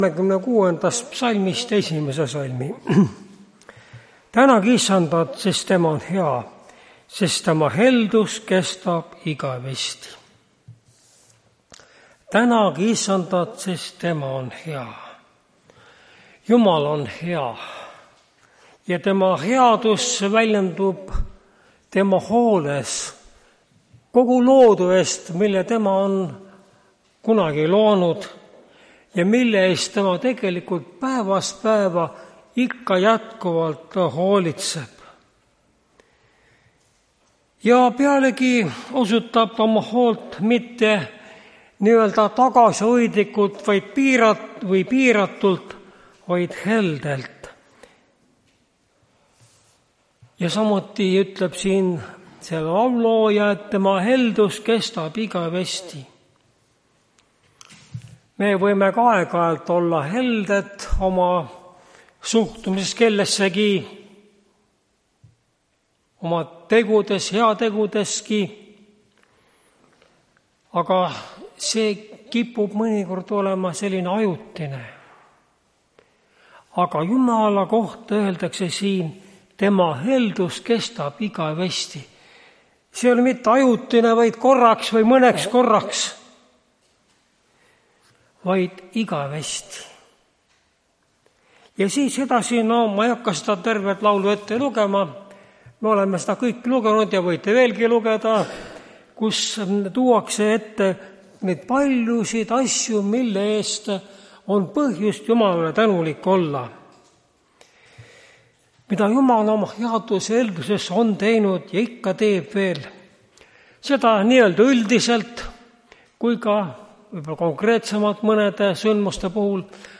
Tartu adventkoguduse 12.10.2024 hommikuse teenistuse jutluse helisalvestis.
Jutlused